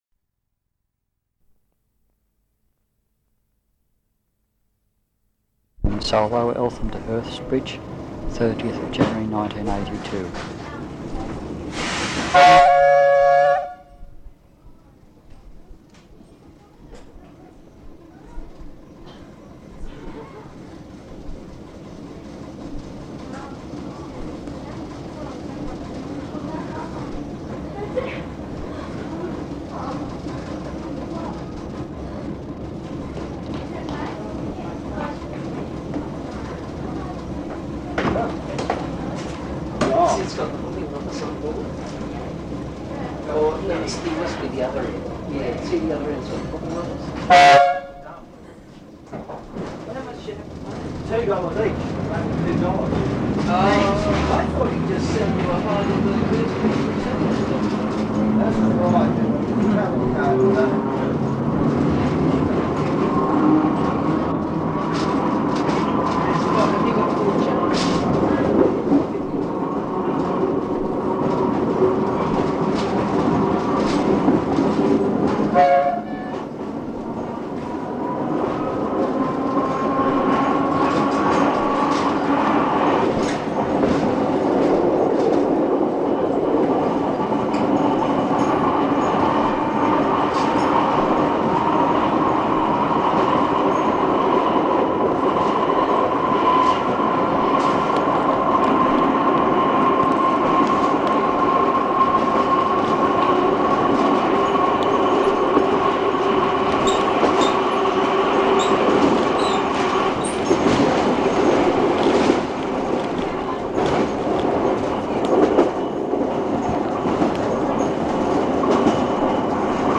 This is what they called a tait train, An old wooden electric set which were the first on the Melbourne network. Apart from the traction motors, you will also hear the jingling sound of the handles that people could hold which were on leather straps that would swing wildly as the train moved.